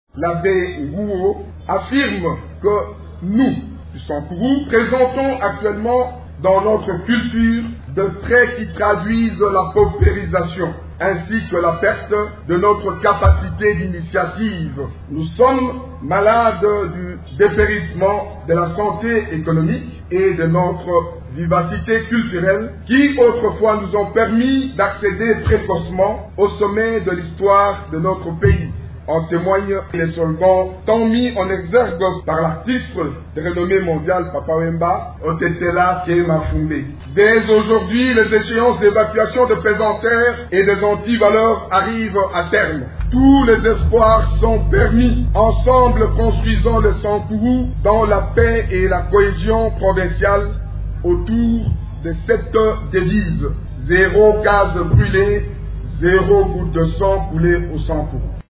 Le commissaire spécial de la nouvelle province du Sankuru, Bertold Ulungu, appelle à la cohésion de toutes les forces vives pour le développement de leur entité. A l’occasion de la cérémonie de remise et reprise, mercredi 18 novembre à Mbuji-Mayi, il a indiqué que le moment était enfin venu pour tourner la page des antivaleurs  et des violences, qui ont longtemps endeuillé cette partie de la RDC.
Vous pouvez écouter un extrait de l’allocution du commissaire spécial du Sankuru ici: